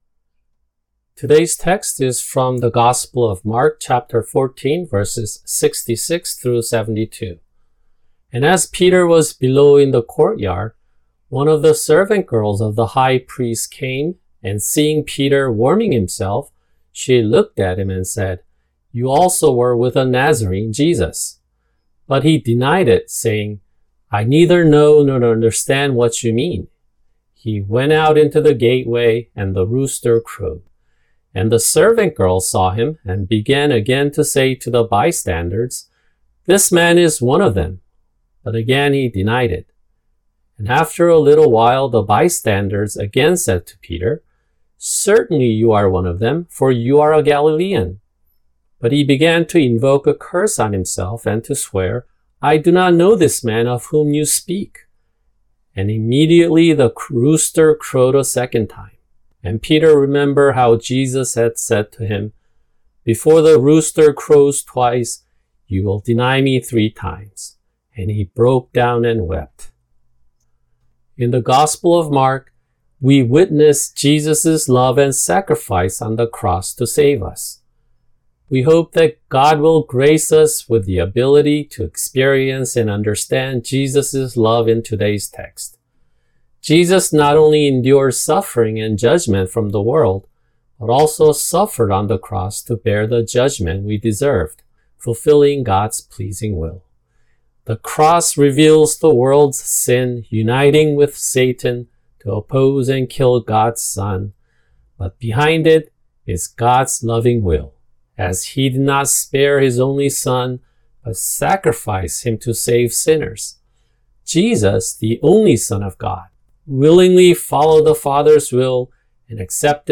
[주일 설교] 마가복음(71) 14:66-72